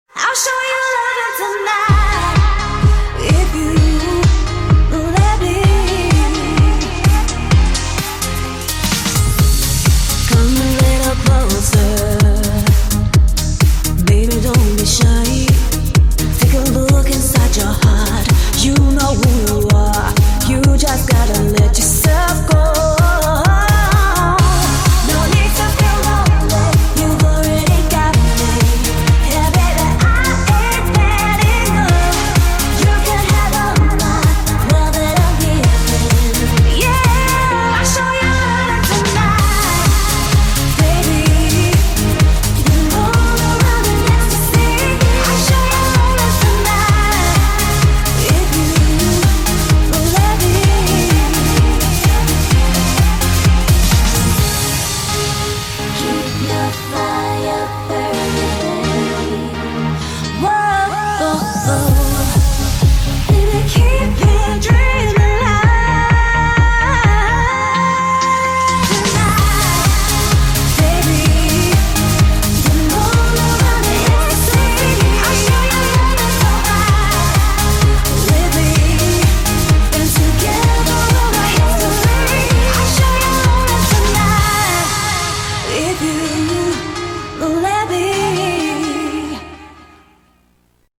BPM128
Audio QualityPerfect (High Quality)
CommentsIt's quite relaxing to listen to this song.